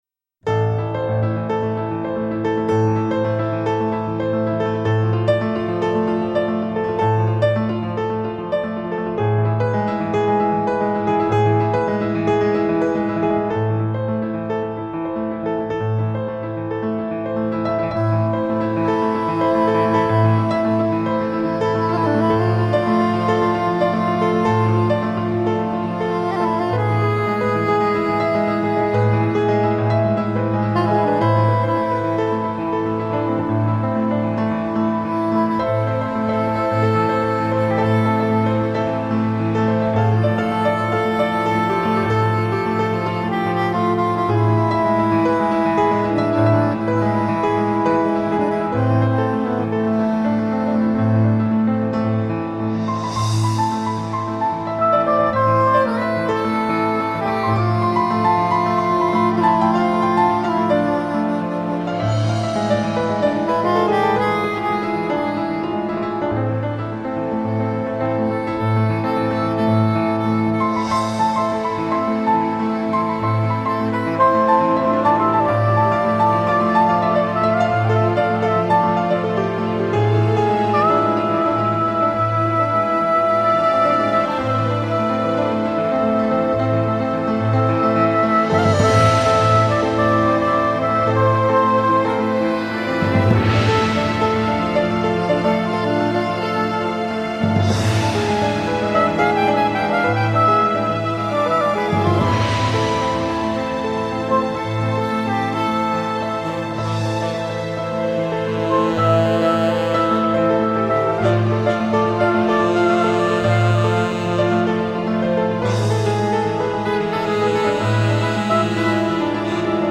★新世纪巴洛克主义
穿越时空轮回、永志不渝的钢琴恋曲